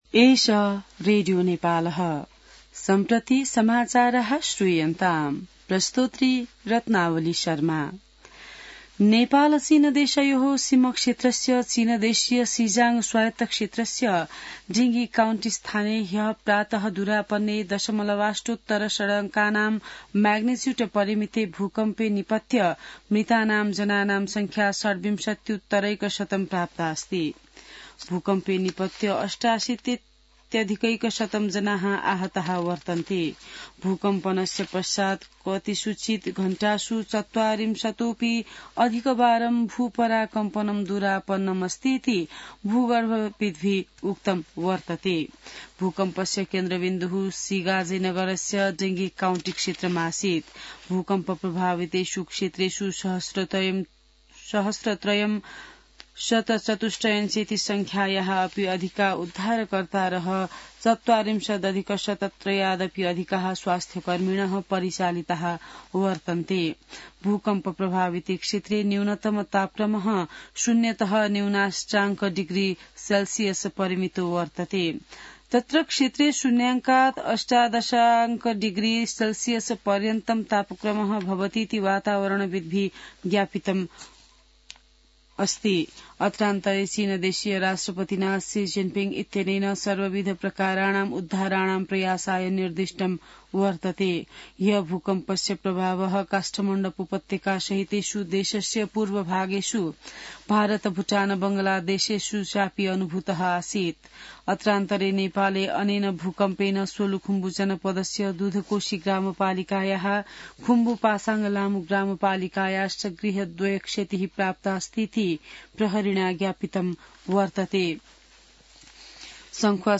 संस्कृत समाचार : २५ पुष , २०८१